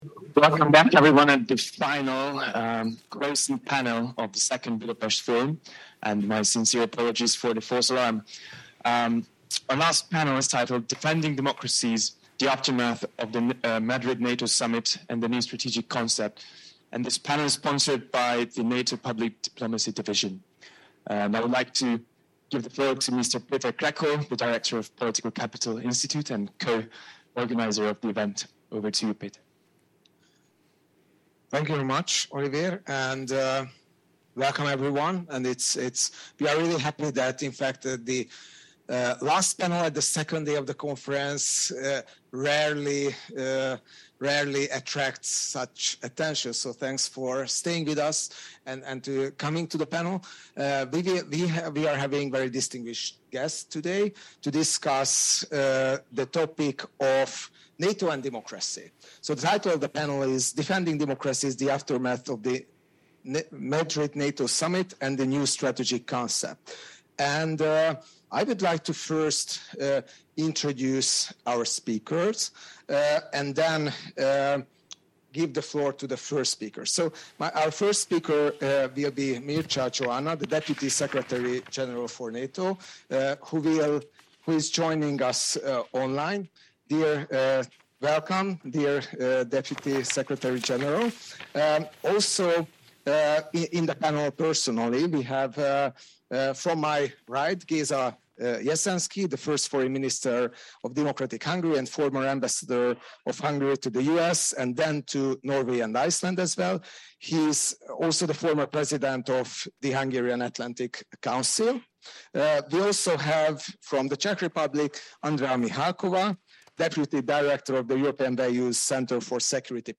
Dans un discours prononcé ce jeudi (22 septembre 2022) en ouverture du Forum de Budapest, le secrétaire général délégué de l’OTAN, Mircea Geoană, a évoqué l’engagement de l’OTAN à l’égard de l’Ukraine.